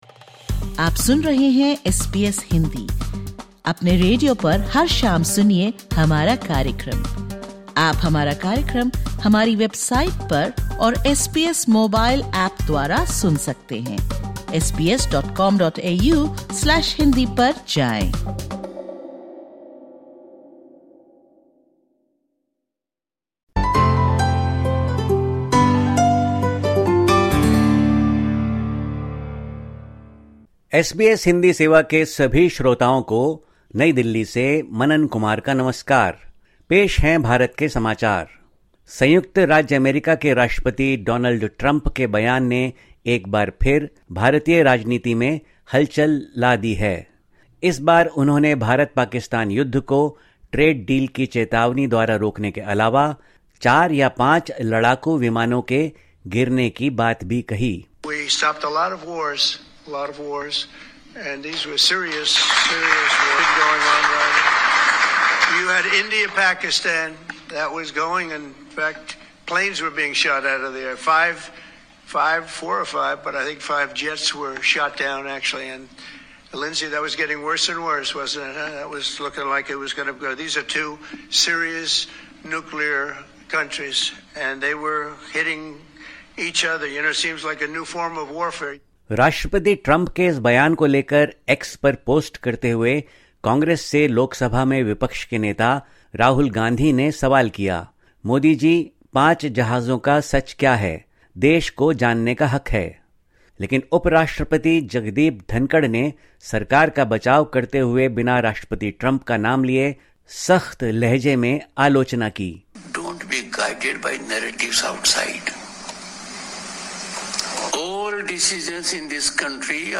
Listen to the latest SBS Hindi news from India. 21/07/25